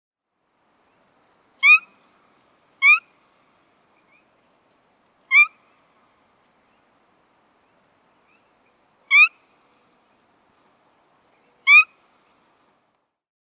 На этой странице собраны звуки свиристели – красивые и звонкие трели этой яркой птицы.
Песня темного свиристеля